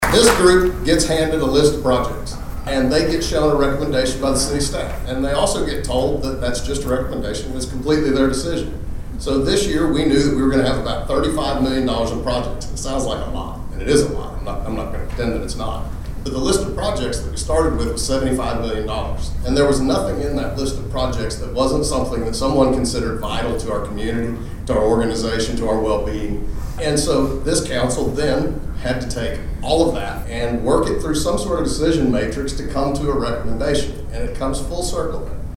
During a presentation at the Bartlesville Chamber of Commerce's State of the City forum Tuesday at Tri County Tech, City Manager Mike Bailey presented details on the projects that are included in the five propositions voters will see on their ballot.